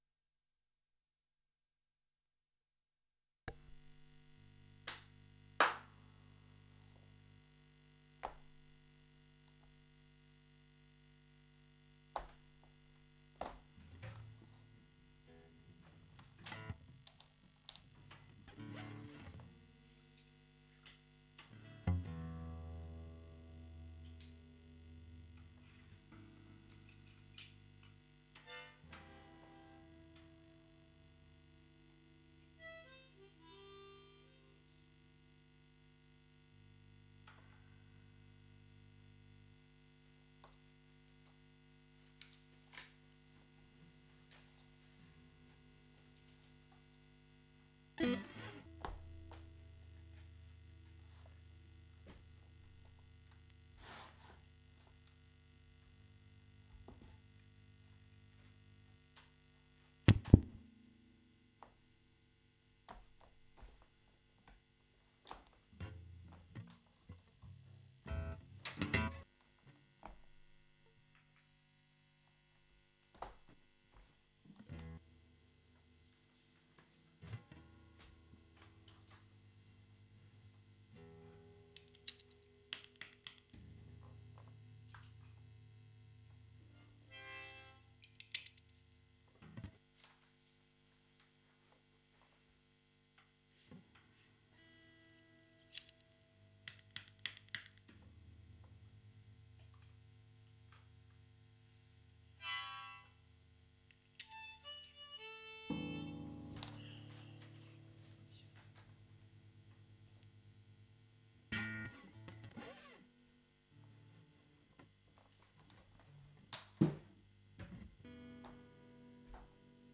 2015 Leerlaufcuts (Studio-Aufnahmen Leerstellen)